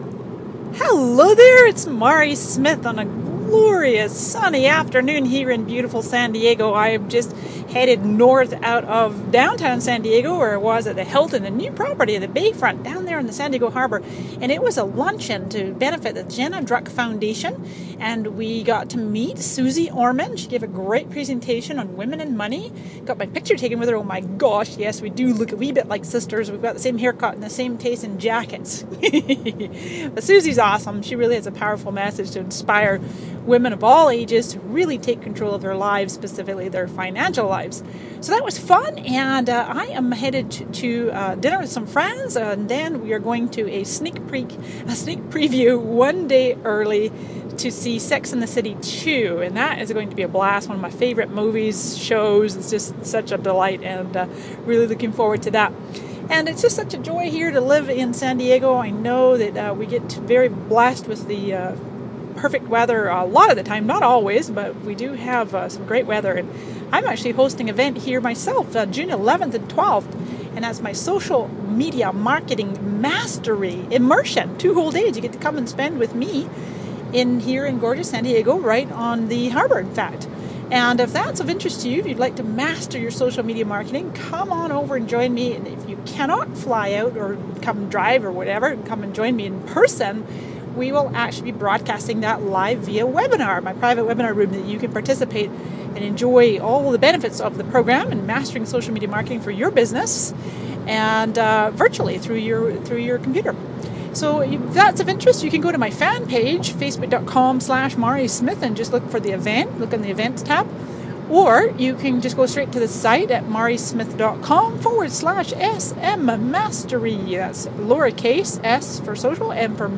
Voice message